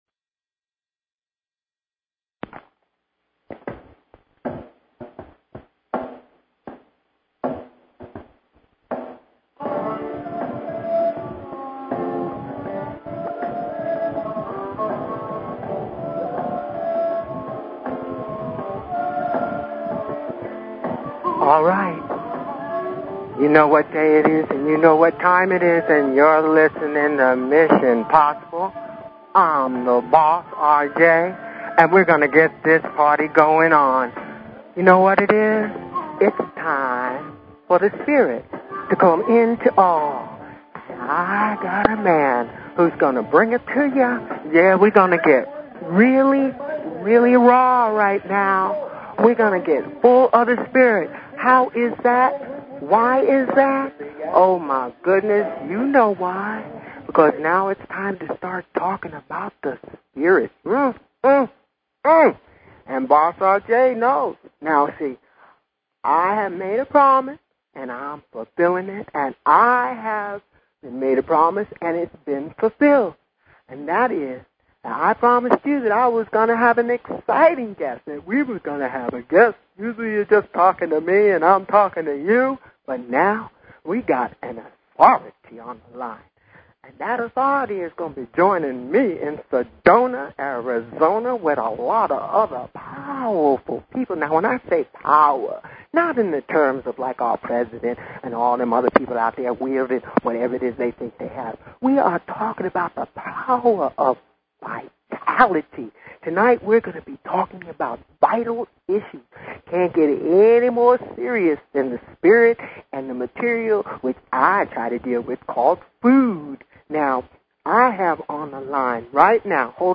Talk Show Episode, Audio Podcast, Mission_Possible and Courtesy of BBS Radio on , show guests , about , categorized as
Interview with David Wolfe author of Sunfood Science Success System